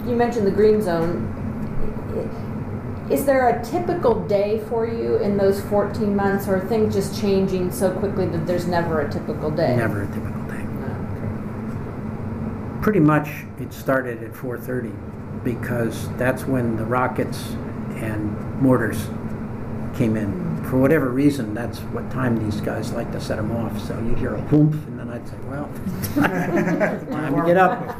He describes life in the “green zone,” an area in the center of Baghdad that the coalition forces controlled. Date: August 28, 2008 Participants L. Paul Bremer III Associated Resources L. Paul Bremer III Oral History The George W. Bush Oral History Project Audio File Transcript